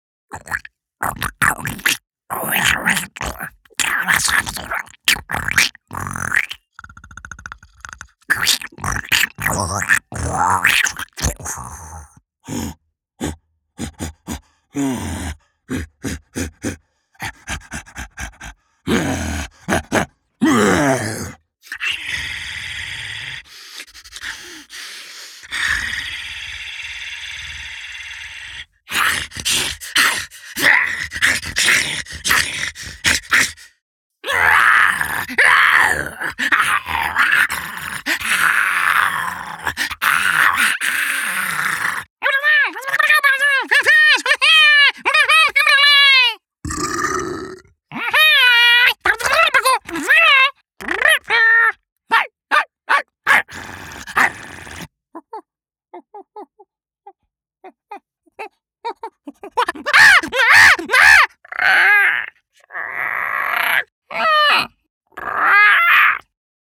Creature Demo